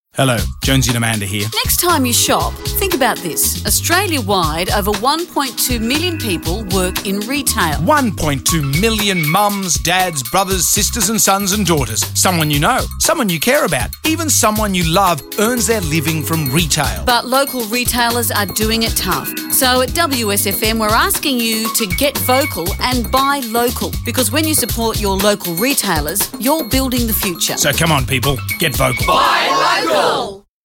The campaign will be run by the stations’ breakfast teams who will voice the promo spots.